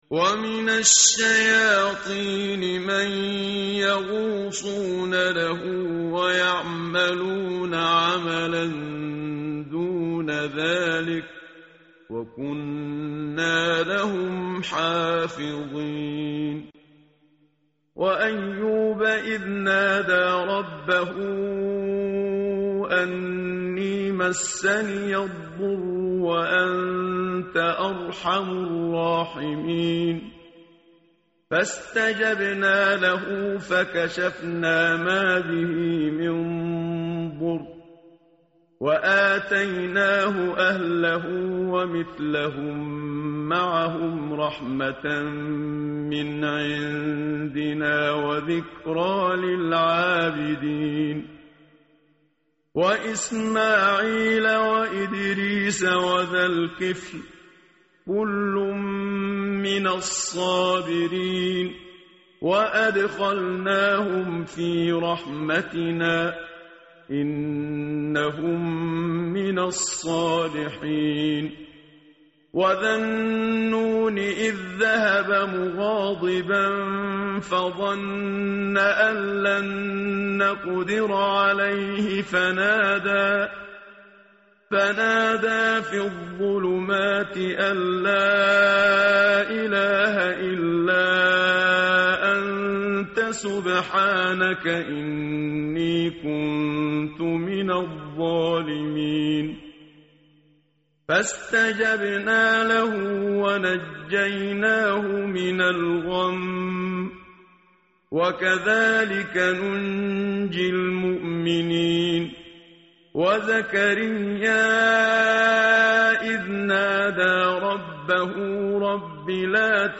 متن قرآن همراه باتلاوت قرآن و ترجمه
tartil_menshavi_page_329.mp3